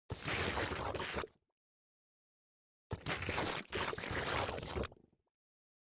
Free SFX sound effect: Mud Slurps.
Mud Slurps
yt_9g8sa7qmudw_mud_slurps.mp3